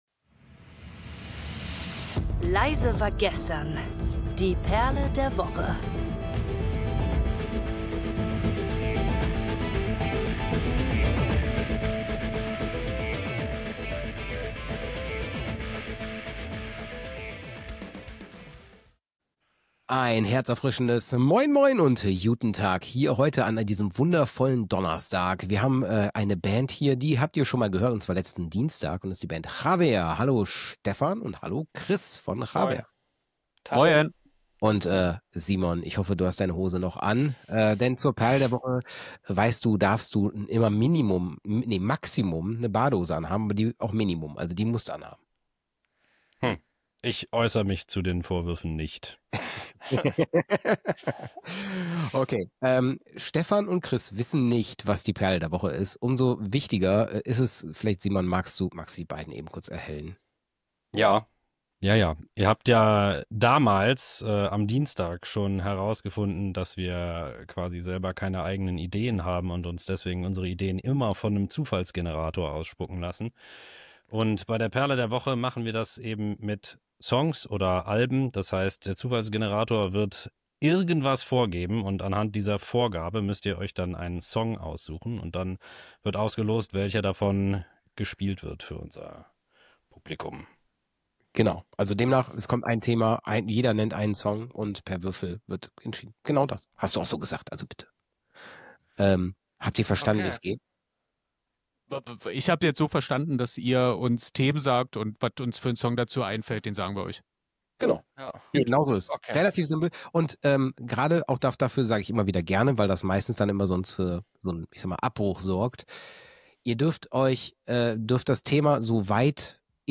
Locker, laut und mit einer Prise Baustellen-Feeling geht’s in dieser Episode nicht nur um Musik, sondern auch um die Frage, was Songs eigentlich mit uns machen – und warum manche Titel besonders tief gehen.